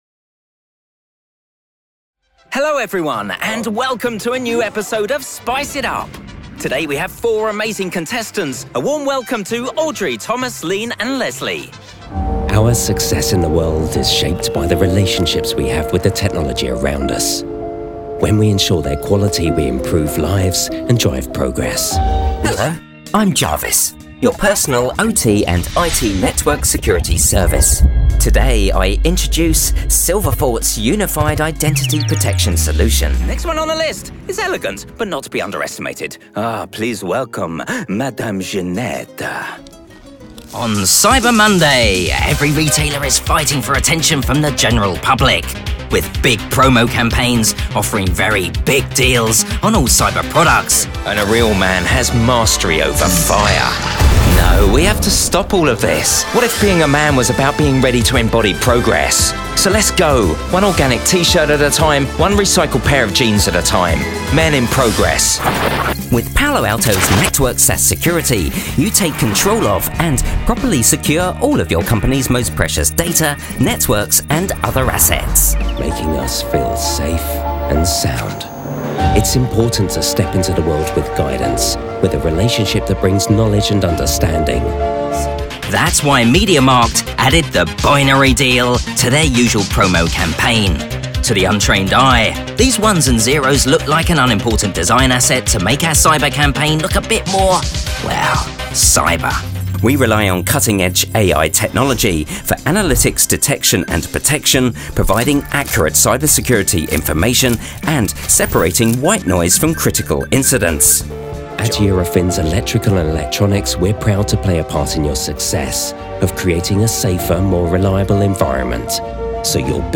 Englisch (Britisch)
Cool, Freundlich, Warm, Kommerziell, Corporate
Unternehmensvideo